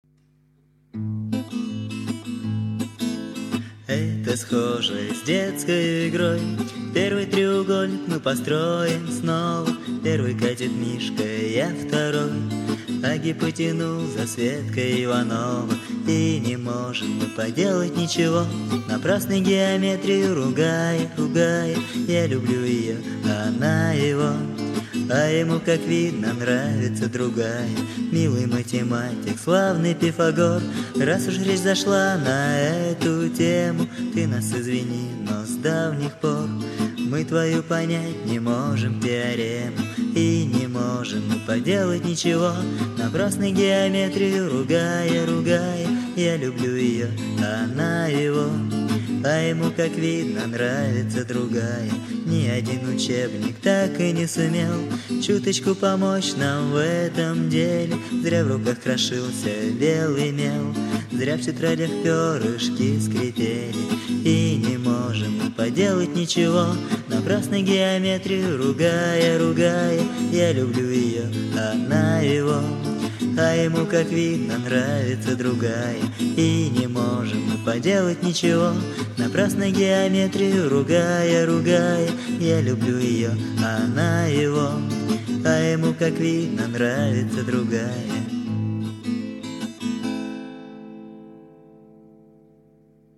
Только тут дуэт, а там один поет, но ритм такой же.